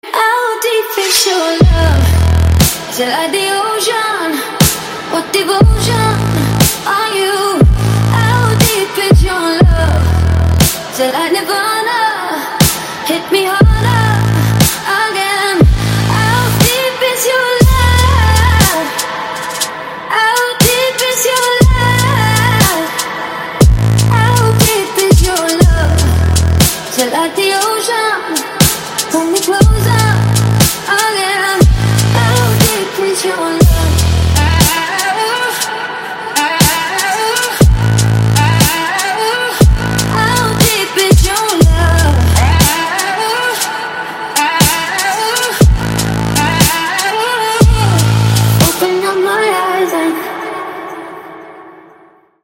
Громкие рингтоны